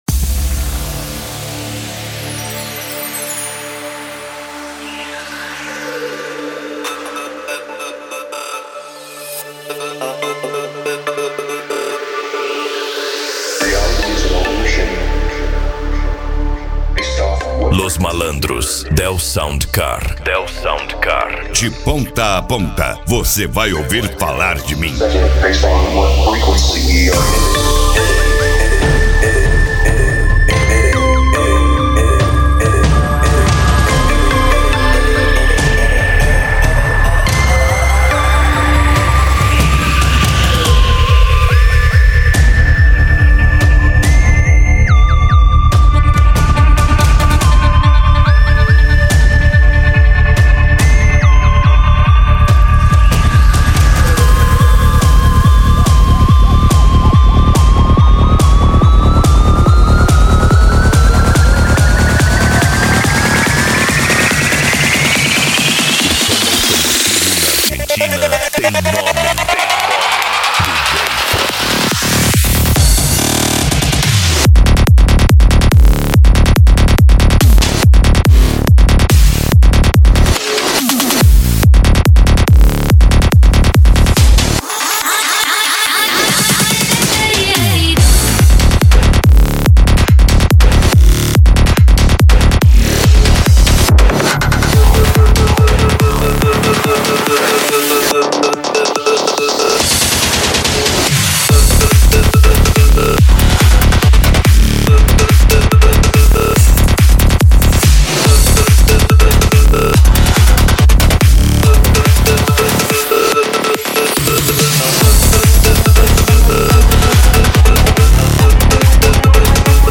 Bass
Eletronica
Psy Trance